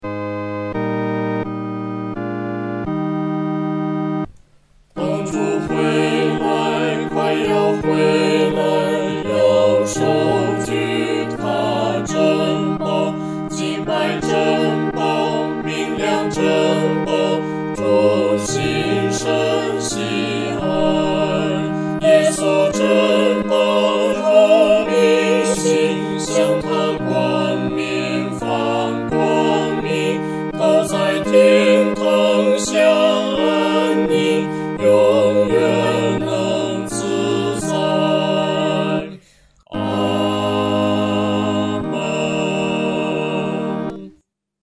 合唱（四声）